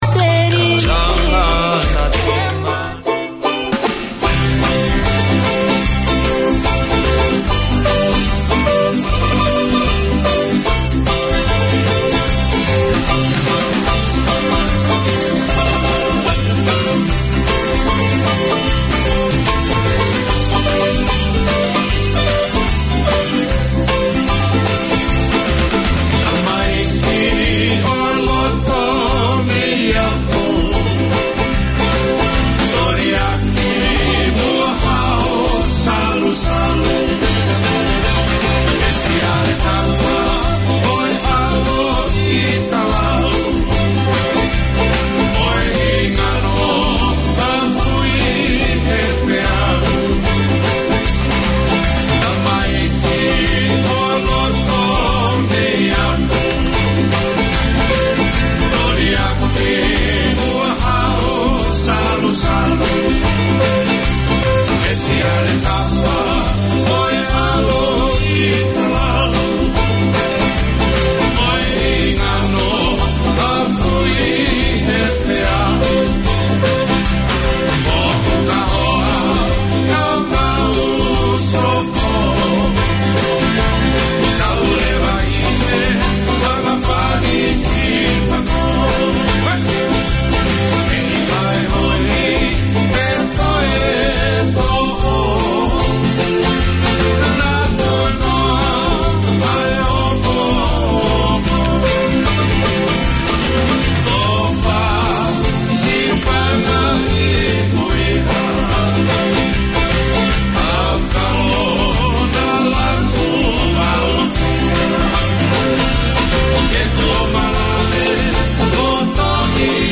A new era in Tongan broadcasting, this is a talk show that focuses on community successes and debating issues from every angle relevant to Tongan wellbeing. Four mornings a week, the two hour programmes canvas current affairs of concern to Tongans and air in-depth interviews with Tongan figureheads, academics and successful Tongans from all walks of life.